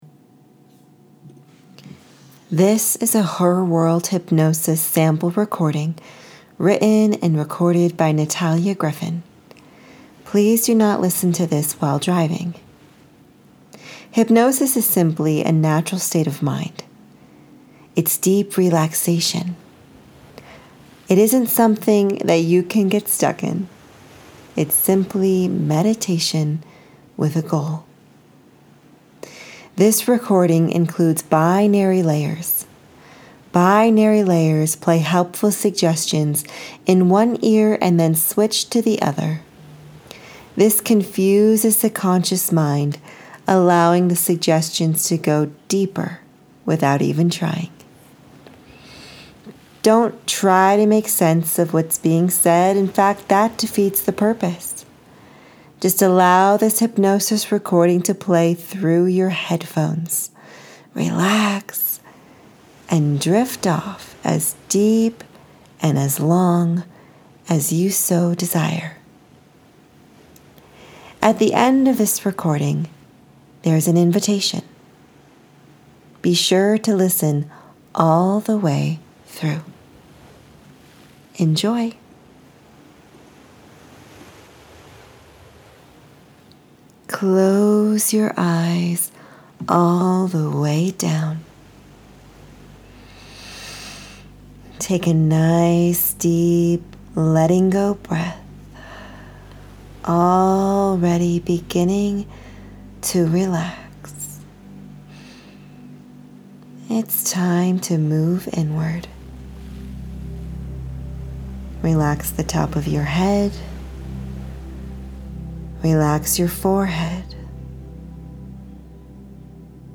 Your Hypnosis Experience Awaits...